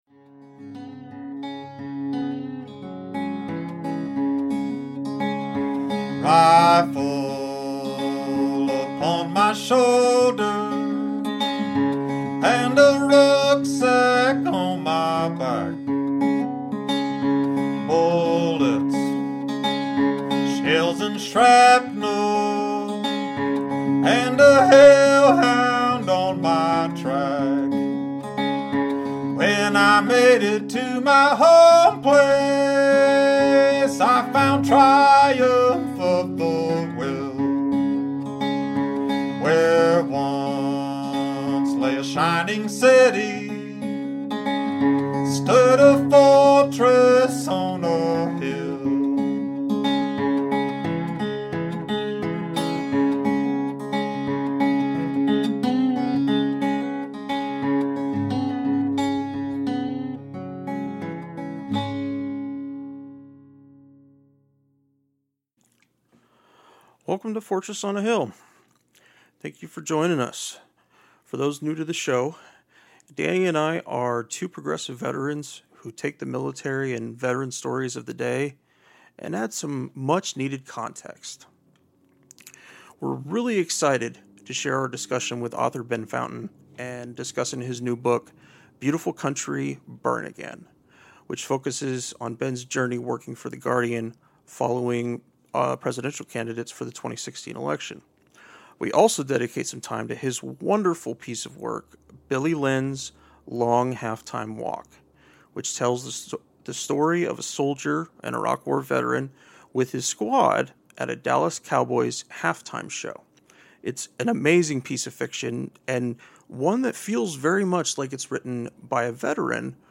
Seeking truth among the ruins of disaster – Ben Fountain interview – Ep 24